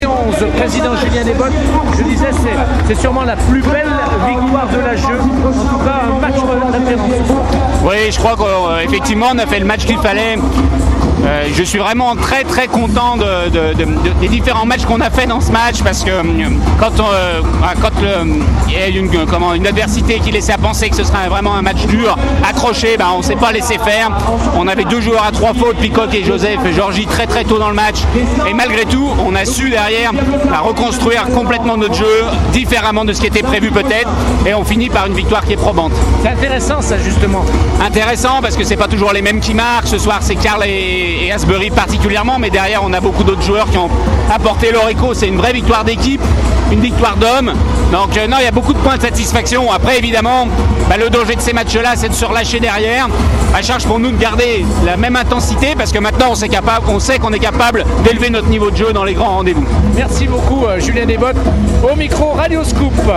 On écoute les réactions d’après-match au micro Radio Scoop